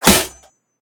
metal2.ogg